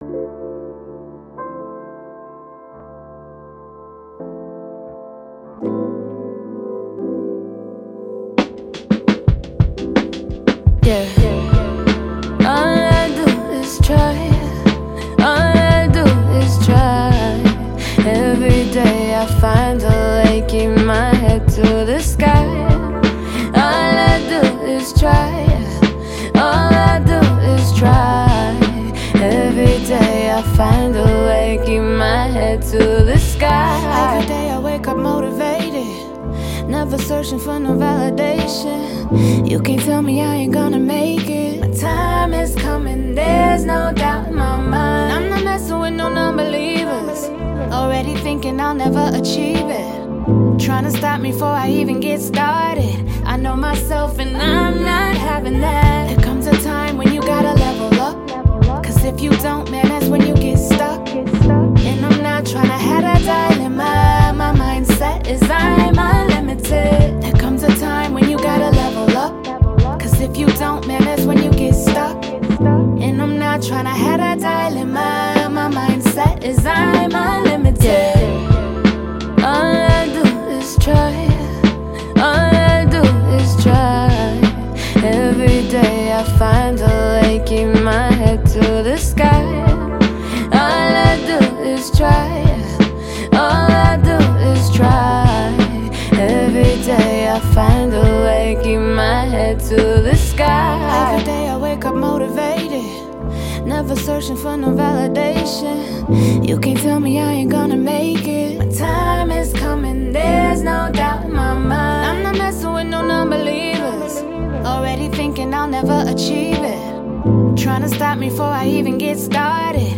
R&B, Hip Hop, Boom Bap